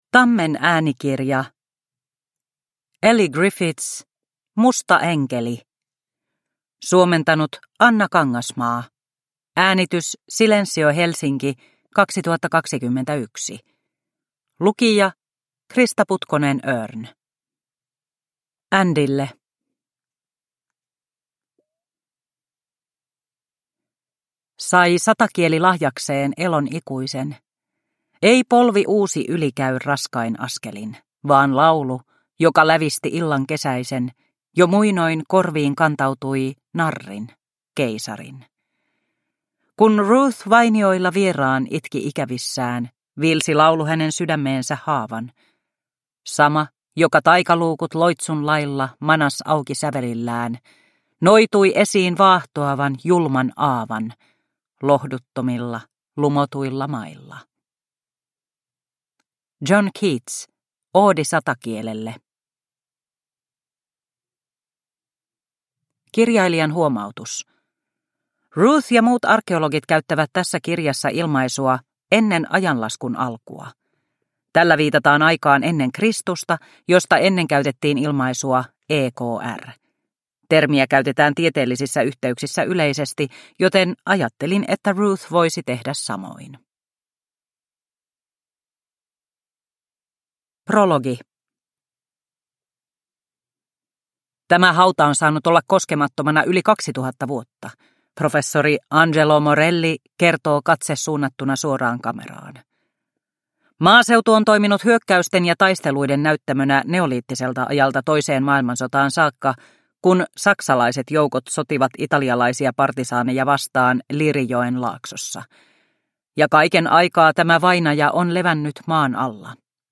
Musta enkeli – Ljudbok – Laddas ner